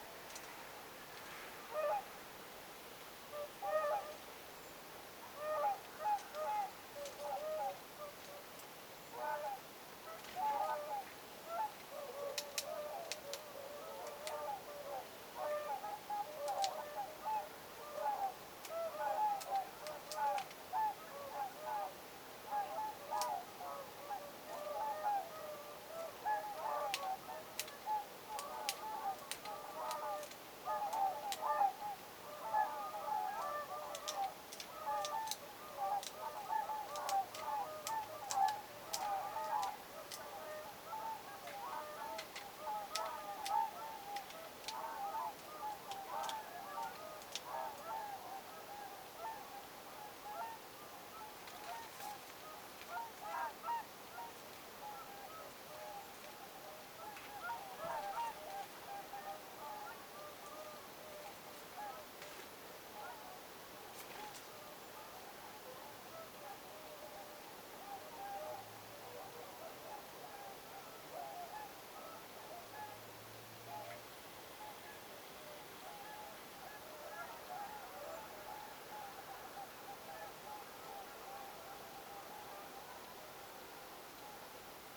sinitiaiset rapistelevat ruovikossa
Siitä kuuluu joskus rapistelua, kun ne
sinitiaiset_rapistelevat_ruovikossa_etsiessaan.mp3